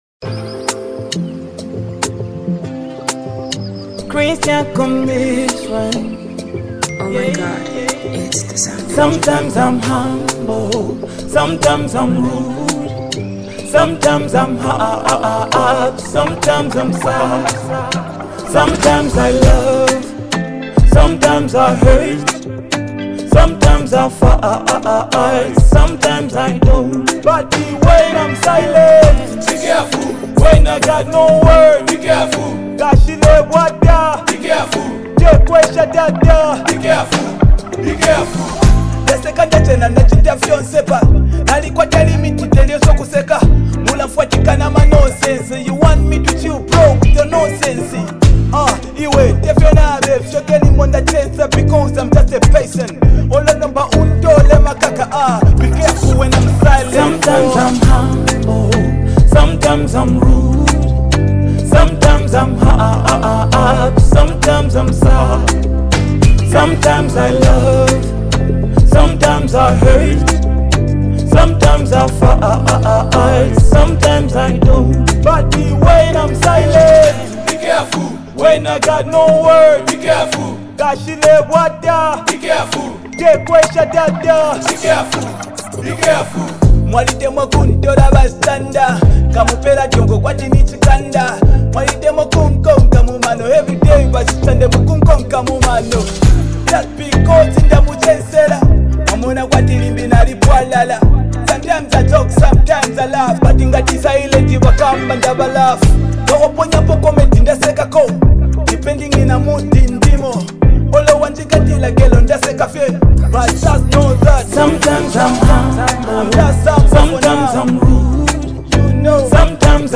merging two distinct styles into one harmonious hit.
Genre: African Music